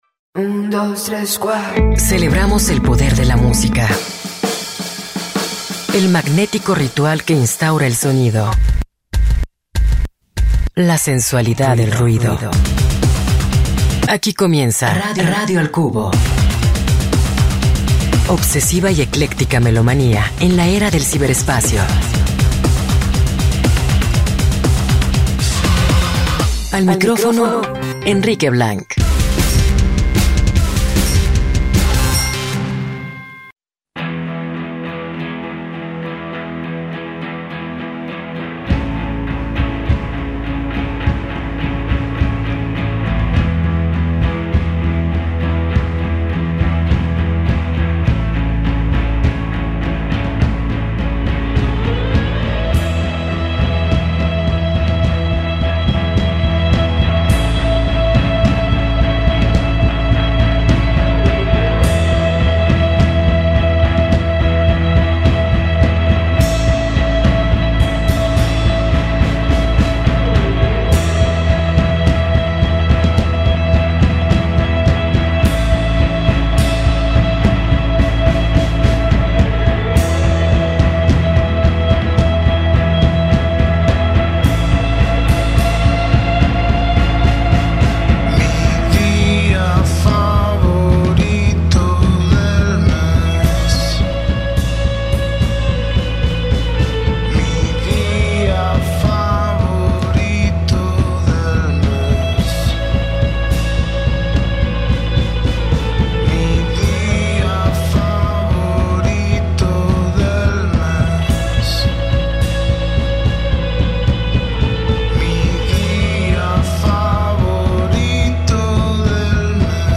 La dosis exacta de rock para desconectarte del ruido y entrarle a lo que importa: música con carácter.